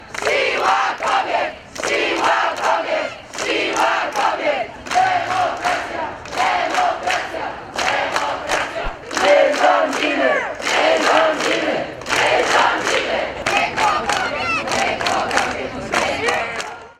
Suwalczanie zgromadzili się w Parku Konstytucji 3 Maja, a następnie skandując przeszli przed biuro Jarosława Zielińskiego, posła Prawa i Sprawiedliwości.
protest-1.mp3